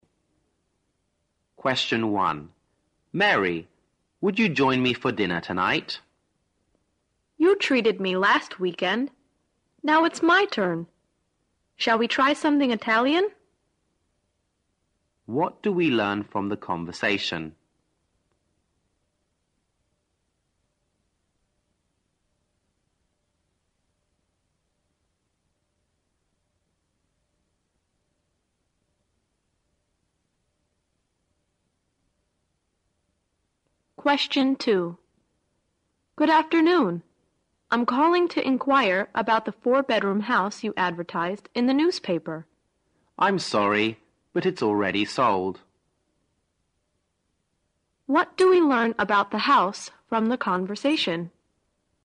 在线英语听力室104的听力文件下载,英语四级听力-短对话-在线英语听力室